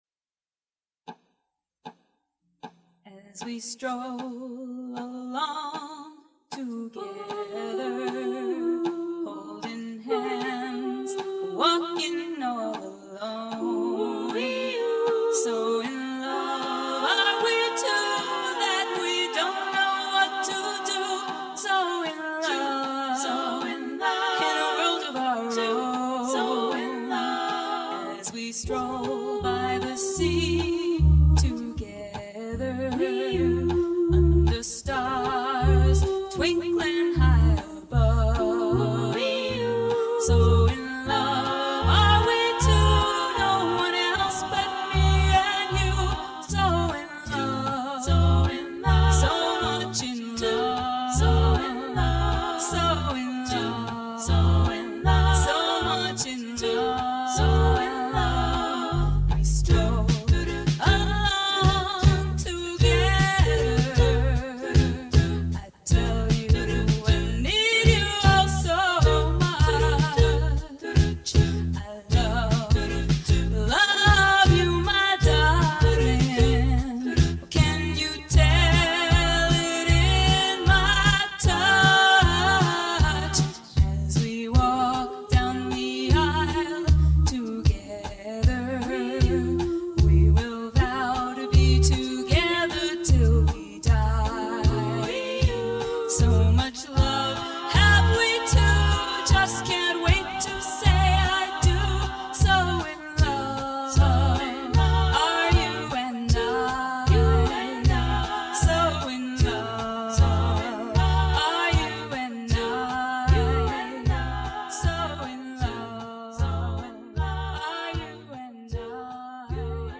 This CD was recorded at TallMan Studios in 1988.
background vocals
bass
drums